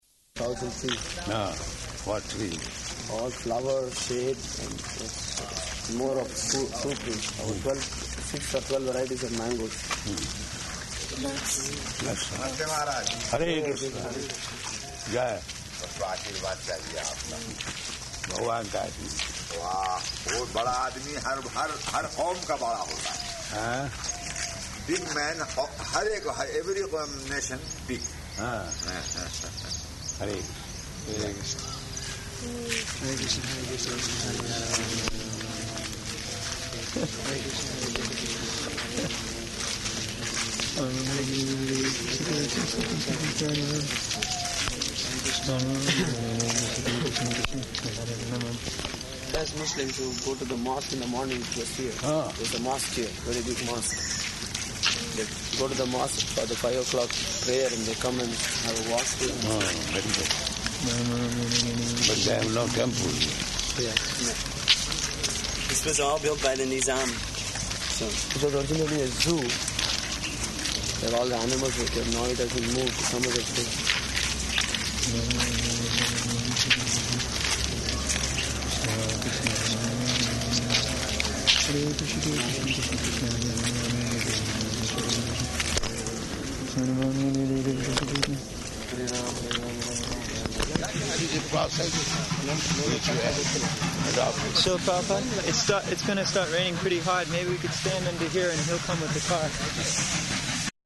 Morning Walk [partially recorded]
Type: Walk
Location: Hyderabad